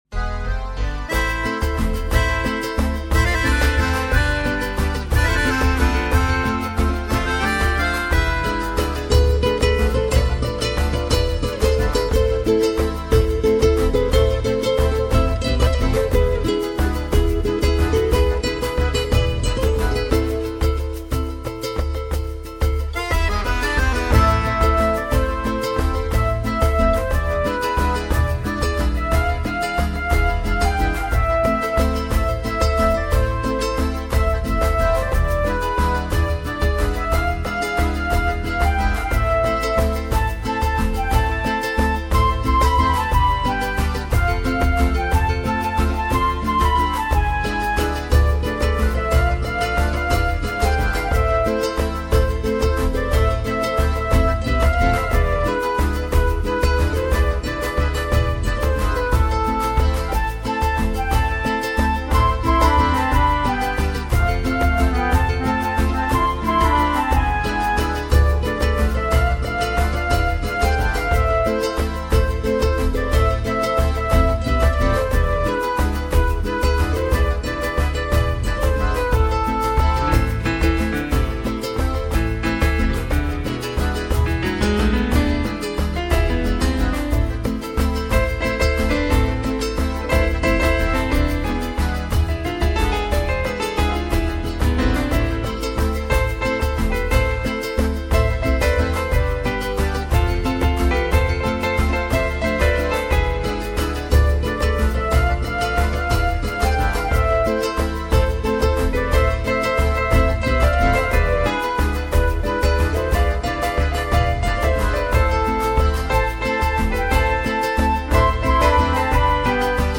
Género: Blues.